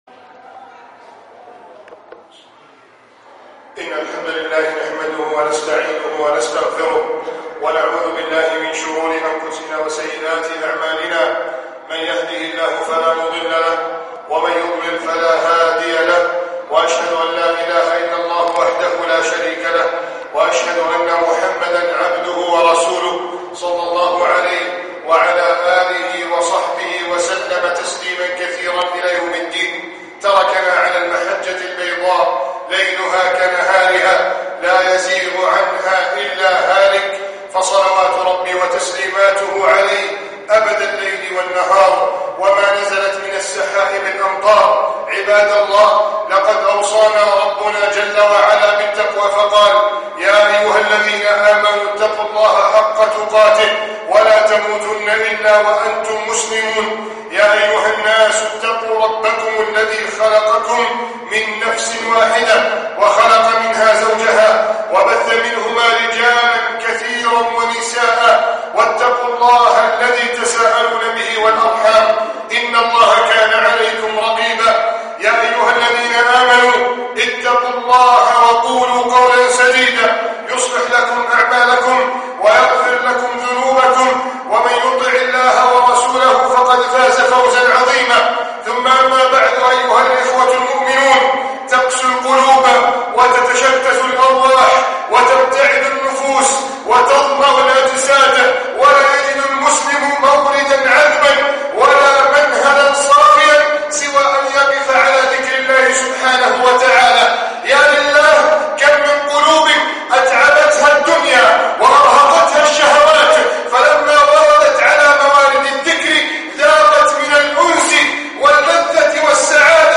السباق إلى الله -خطبة الجمعة